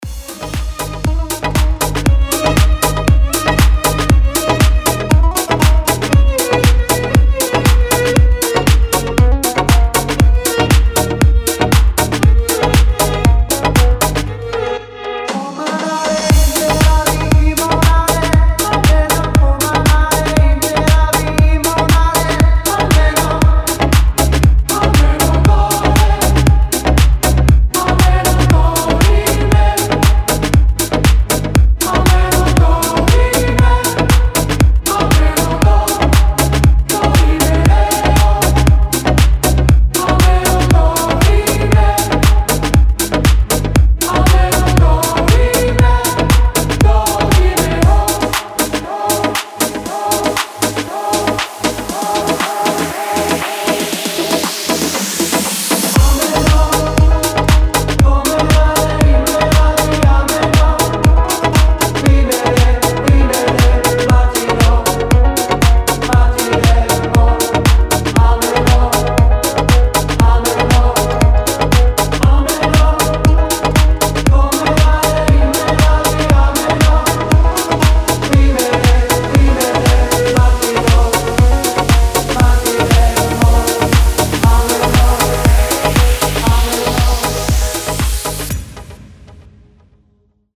• Качество: 320, Stereo
восточные мотивы
красивая мелодия
Cover Mix